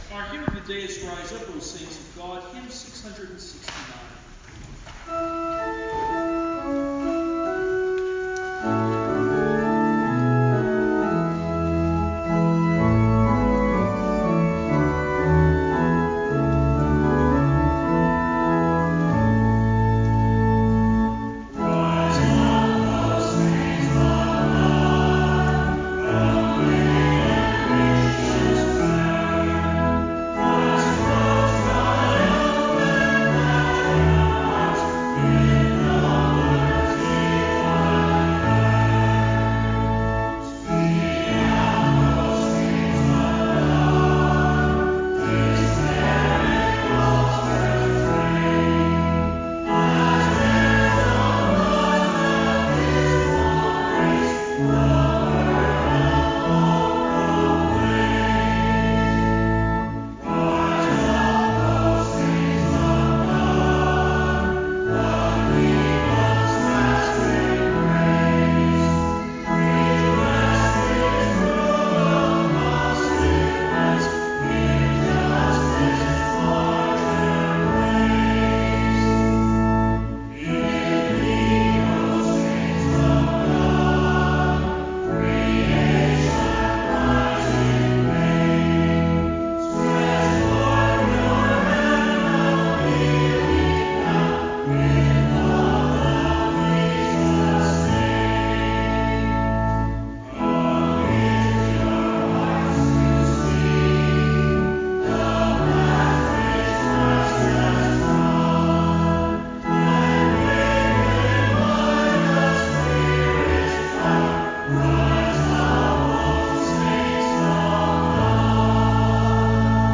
Sermon-5-5-CD.mp3